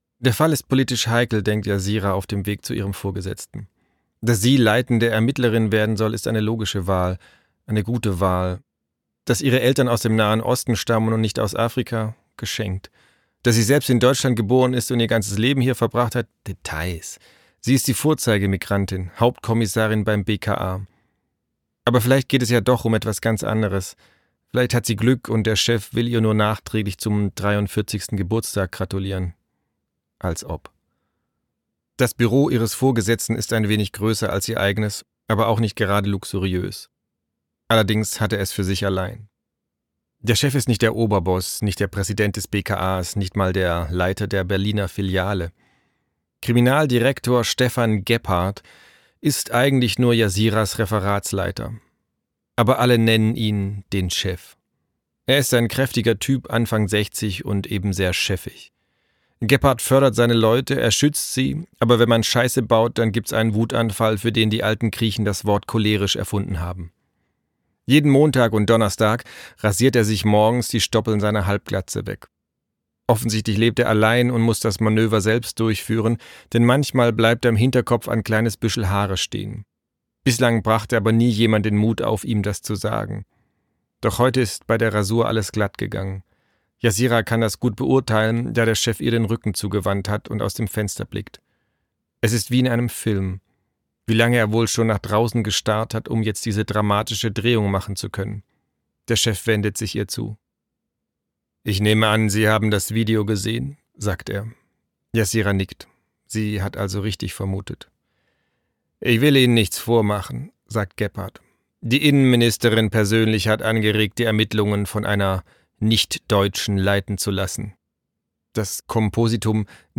Sprecher Marc-Uwe Kling